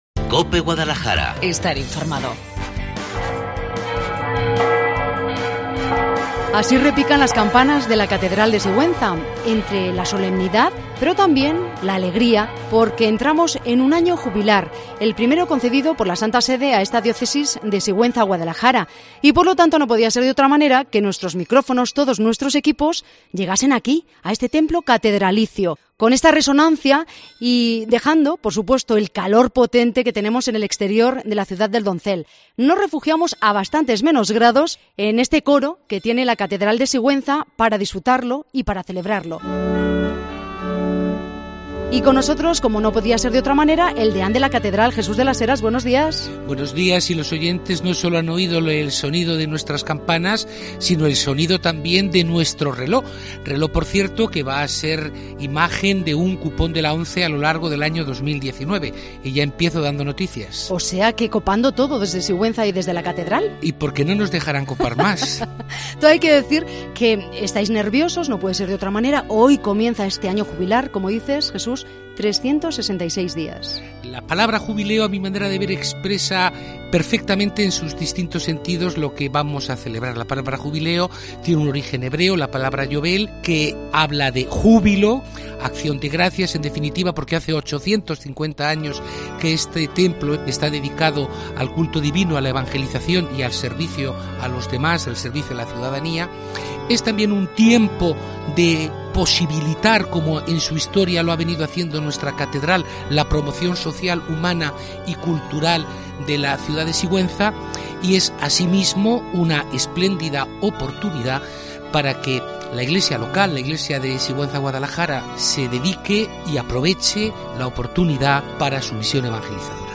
La Mañana en COPE en Guadalajara Cope Guadalajara se traslada a la Catedral de Sigüenza en el comienzo del Año Jubilar 1ª parte Hoy, 19 de junio, comienza la celebración del Año Jubilar que la Santa Sede ha concedido, por primera vez, a la Diócesis Sigüenza-Guadalajara, con motivo del 850 aniversario de la consagración de la Catedral seguntina. Hasta este templo catedralicio se han trasladado los micrófonos de Cope Guadalajara para conocer de boca de sus protagonistas todos los detalles de este ciclo de 366 días, repletos de actos litúrgicos, culturales y sociales.